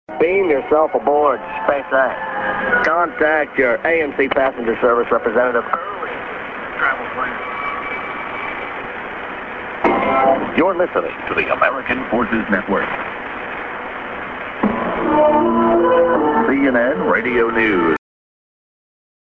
via Guam(Day Time)